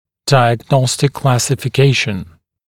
[ˌdaɪəg’nɔstɪk ˌklæsɪfɪ’keɪʃ(ə)n][ˌдайэг’ностик ˌклэсифи’кейш(э)н]диагностическая классификация